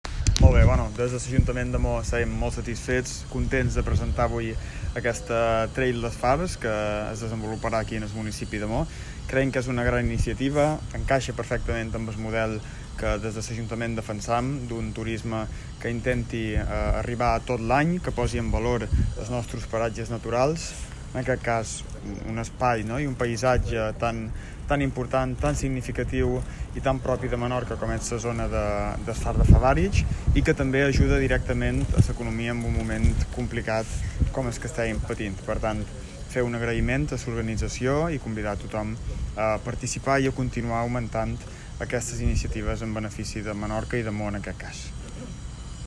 Héctor Pons, Batle de Maó